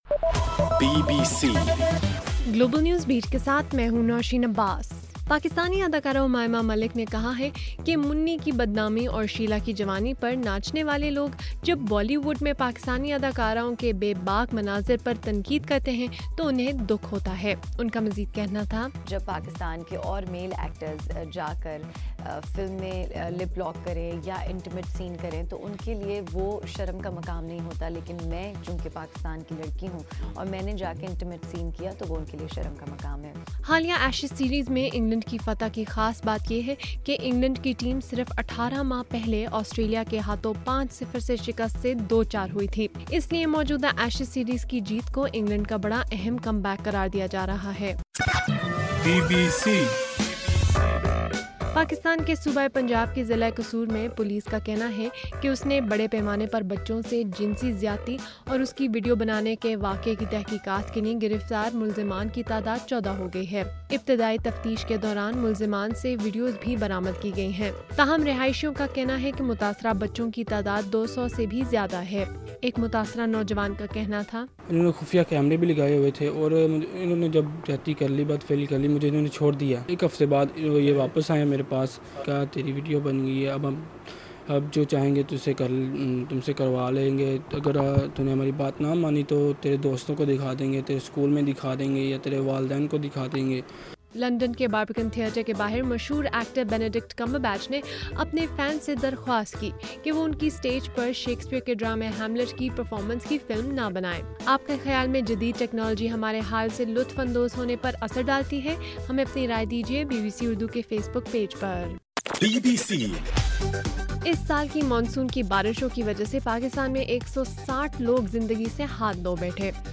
اگست 10: رات 11 بجے کا گلوبل نیوز بیٹ بُلیٹن